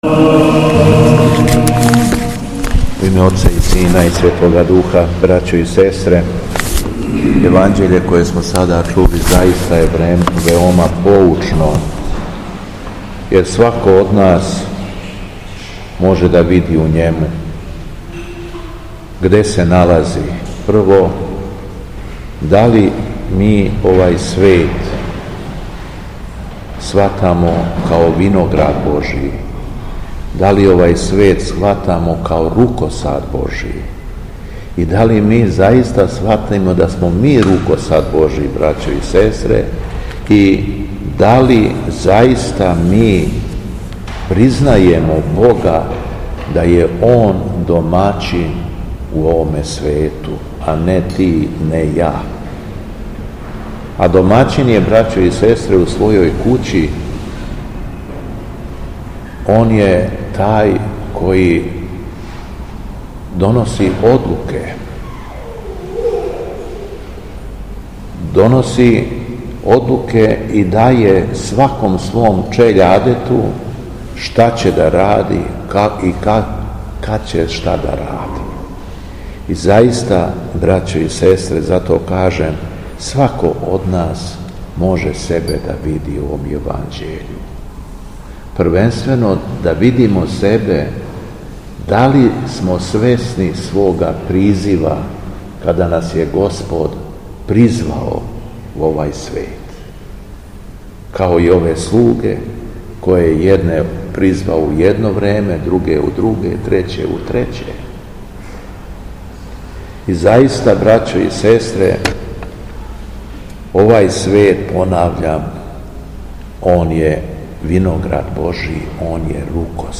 Беседа Његовог Високопреосвештенства Митрополита шумадијског г. Јована
У петак, 17. октобра 2025. године, када наша Света Православна Црква прославља Светог свештеномученика Јеротеја и Светог Стефана Штиљановића, Његово Високопреосвештенство Митрополит шумадијски г. Јован служио је Свету Архијерејску Литургију у храму Свете Петке у крагујевачком насељу Виногради уз саслужење братства овога светога храма.
После прочитаног јеванђелског зачала Високопреосвећени Митрополит се обратио беседом сабраном народу рекавши: